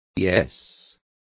Complete with pronunciation of the translation of yeses.